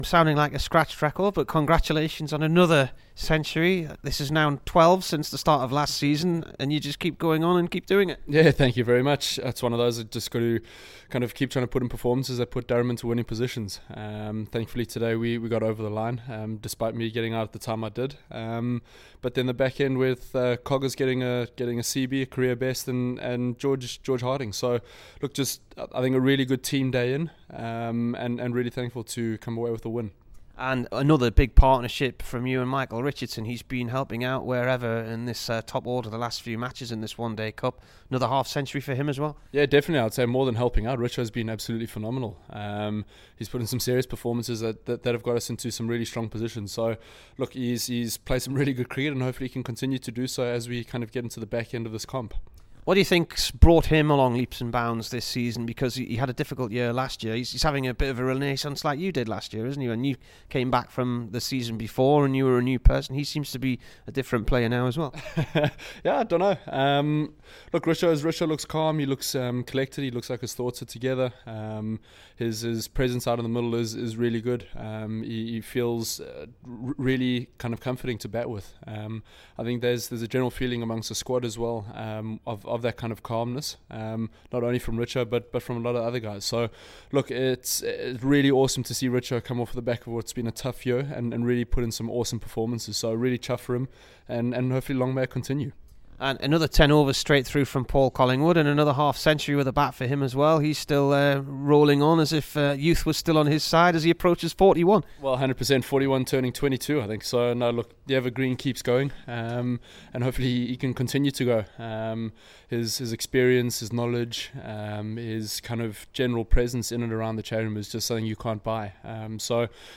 KEATON JENNINGS INT